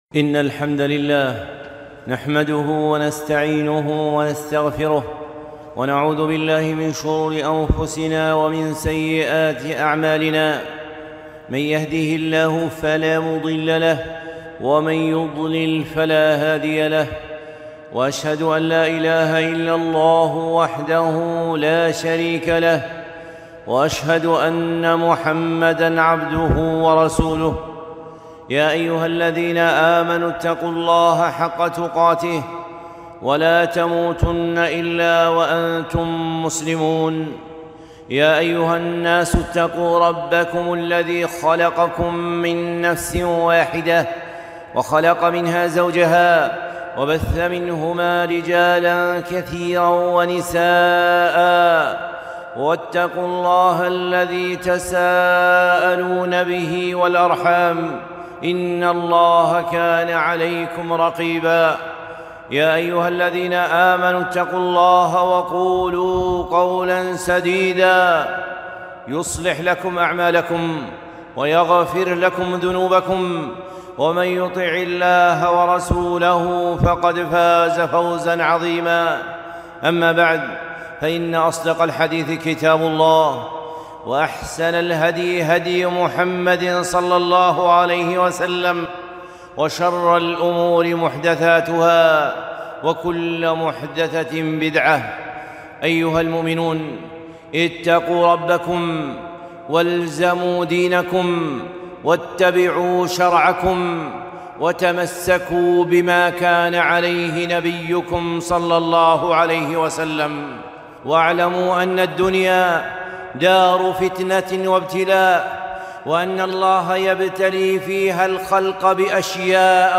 خطبة - الابتلاء بالأعداء